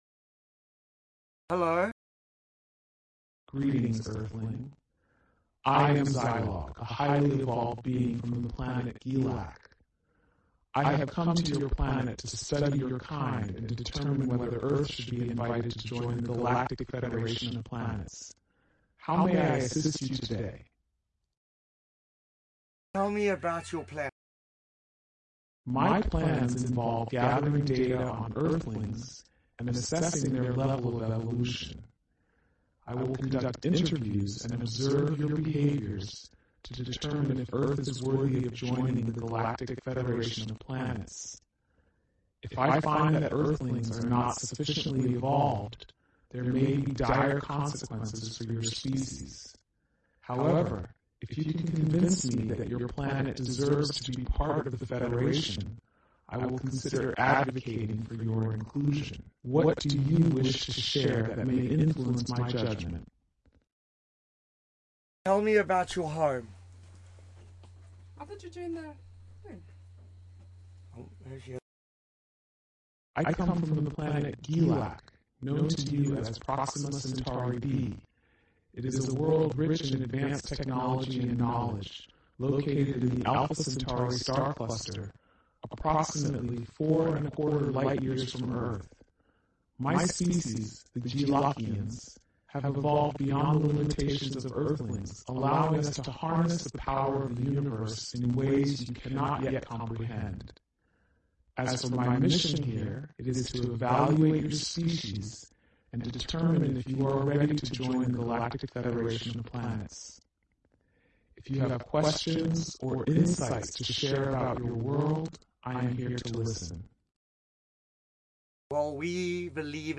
AI Examples / Voice ChatBots with Persona...